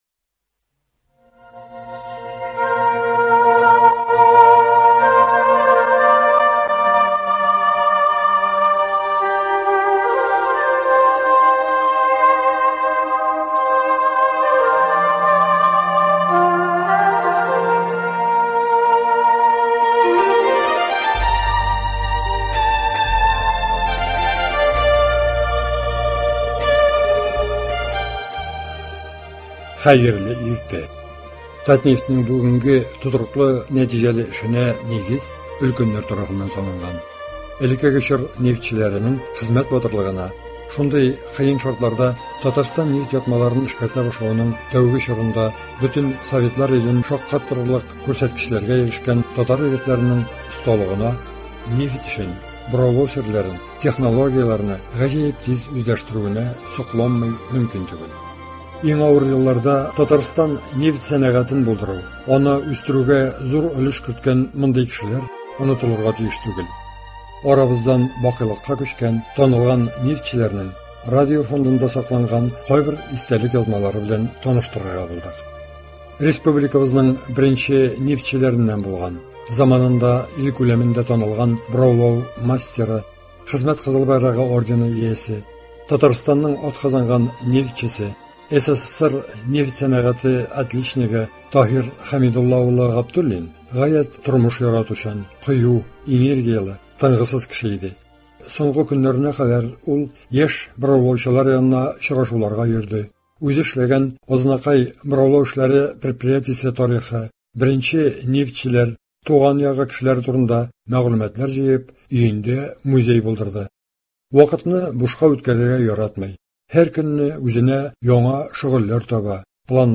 Радио фондыннан.